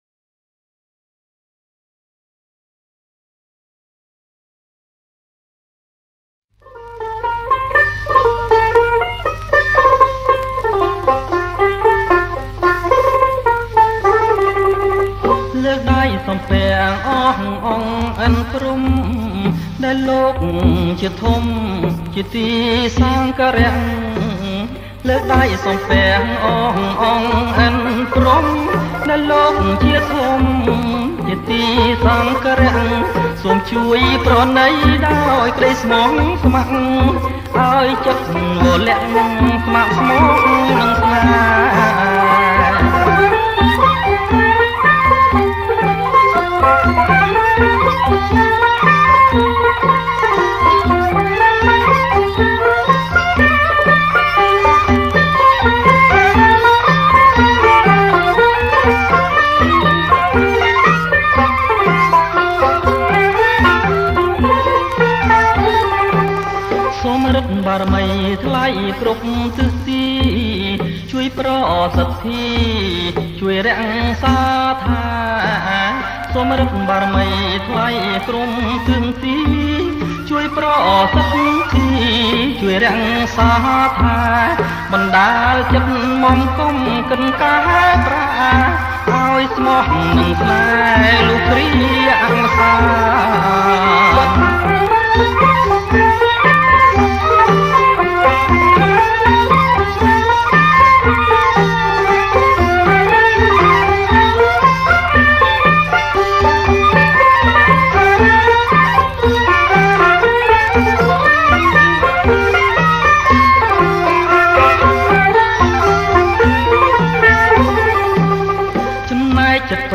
ប្រគំជាចង្វាក់ Bolero ក្បាច់